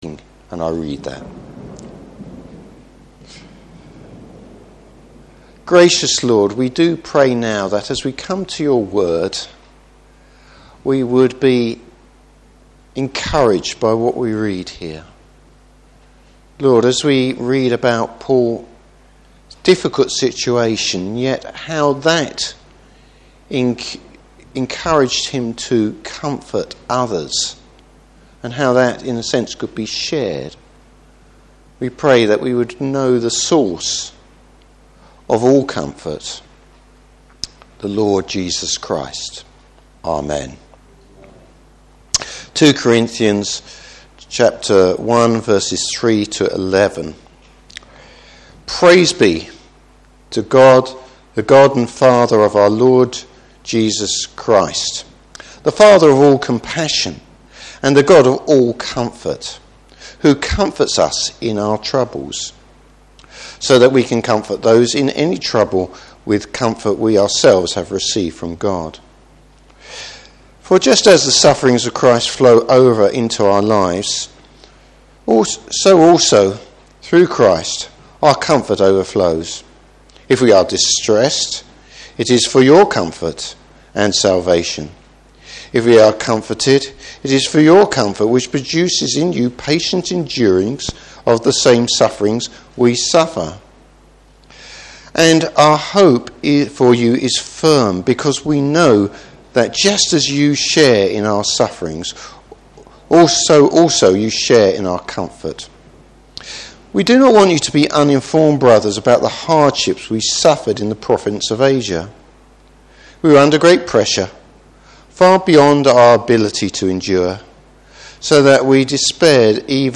Service Type: Morning Service How God gets along side us.